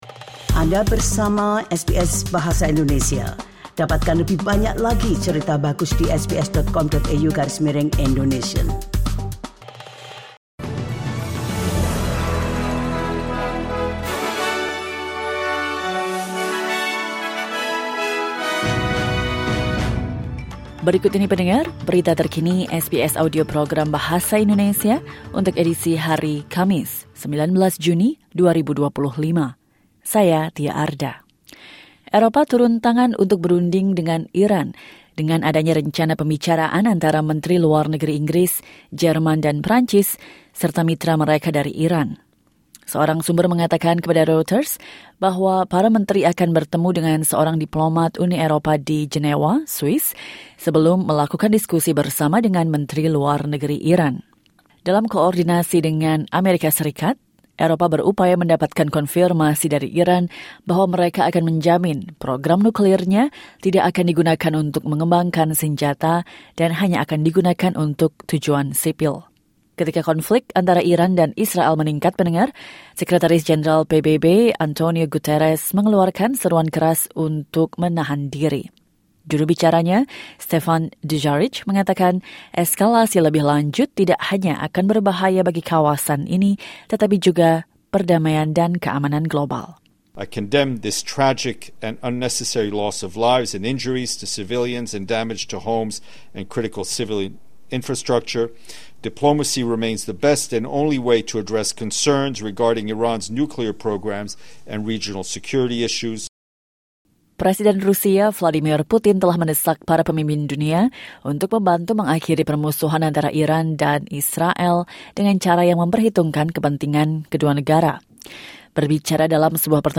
Berita Terkini SBS Audio Program Bahasa Indonesia - 19 Juni 2025